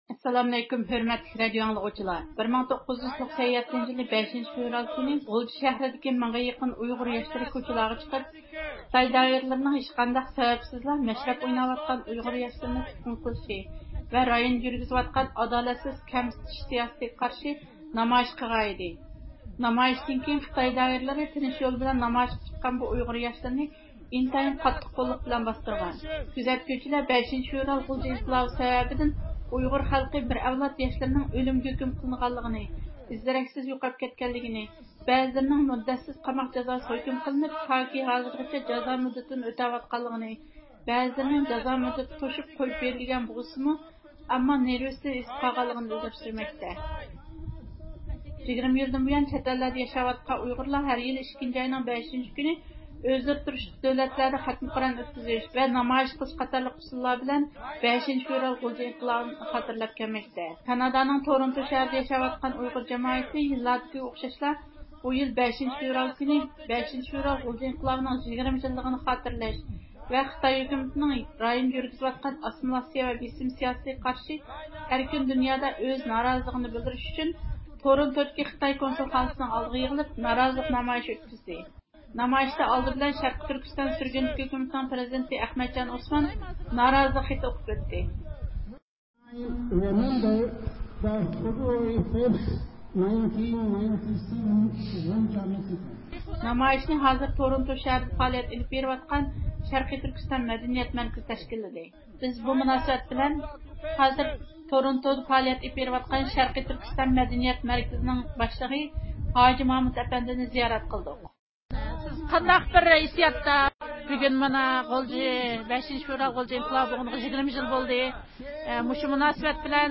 تورونتودىكى ئۇيغۇرلار خىتاي كونسۇلخانىسى ئالدىدا نارازىلىق نامايىشى ئۆتكۈزدى
نامايىشچىلار، «خىتاي شەرقى تۈركىستاندىن چىقىپ كەت، 5-فېۋرال غۇلجا ئىنقىلابى ئۇنتۇلمايدۇ، شېھىتلار ئۆلمەس، خىتاي، ئۇيغۇرلارنى ئۆلتۈرۈشنى توختات»دېگەندەك شوئارلارنى توۋلىغان.
نامايىش جەريانىدا ئەركىن ئاسىيا رادىئوسى مۇخبىرى نامايىش قاتناشچىلىرىنى زىيارەت قىلغان.